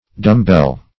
dumbbell \dumb"bell`\, dumb-bell \dumb"-bell`\, n.